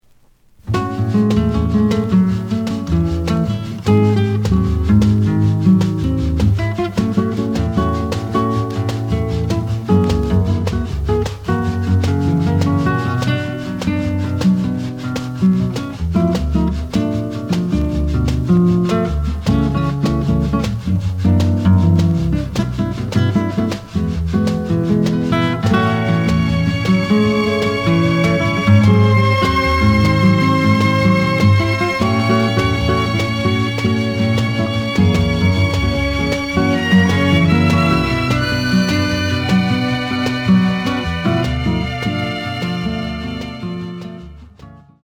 The audio sample is recorded from the actual item.
●Genre: Bossa Nova